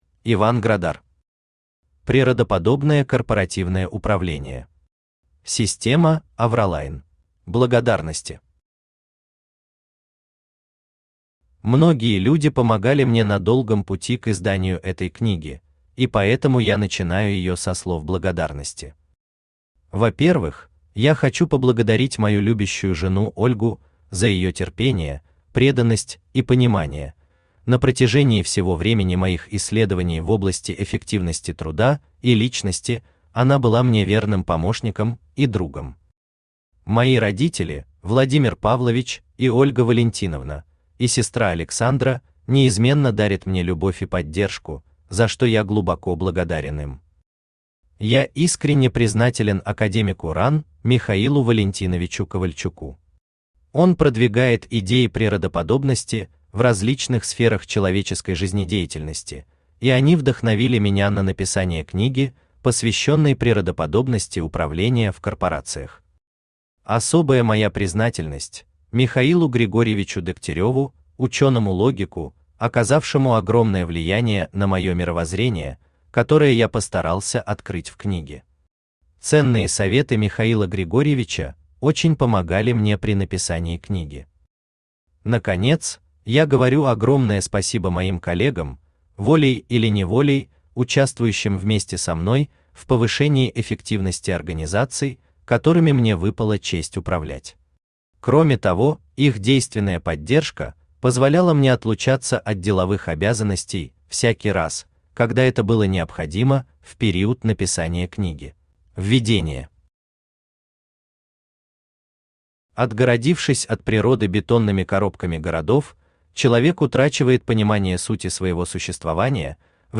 Аудиокнига Природоподобное корпоративное управление. Система «Авраллайн» | Библиотека аудиокниг
Система «Авраллайн» Автор Иван Градар Читает аудиокнигу Авточтец ЛитРес.